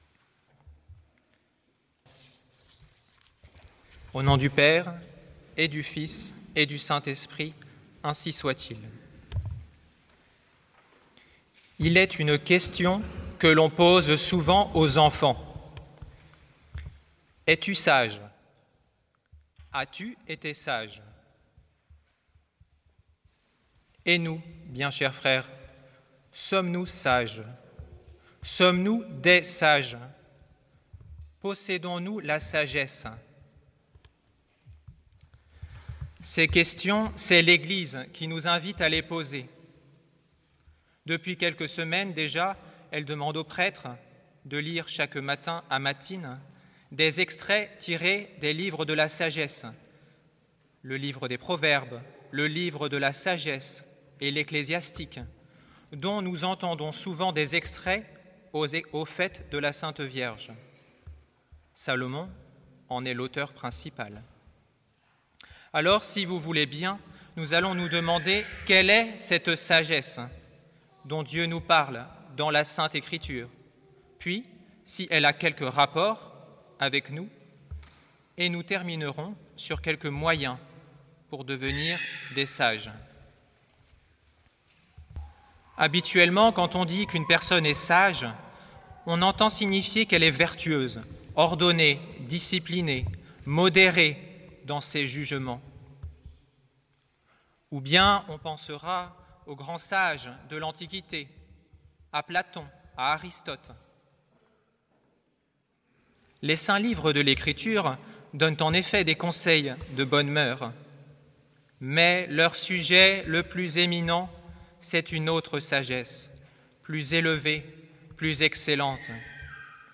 Occasion: Onzième dimanche après la Pentecôte